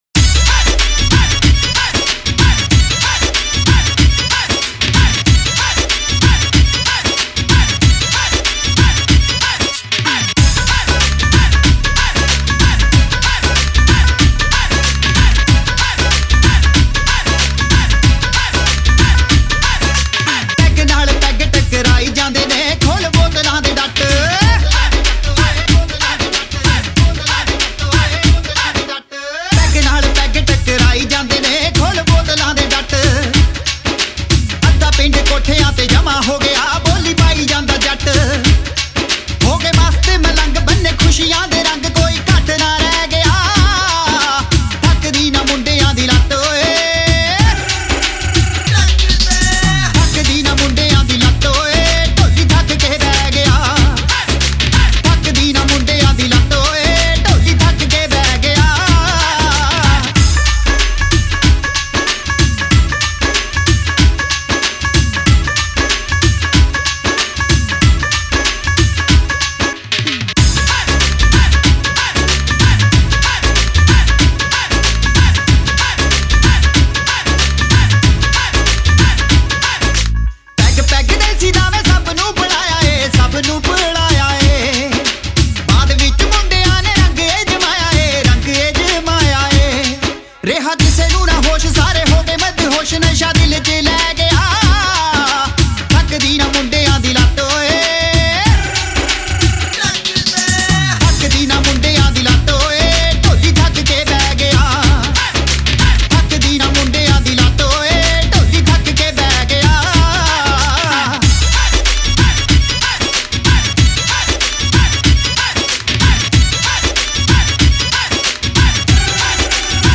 Category: UK Punjabi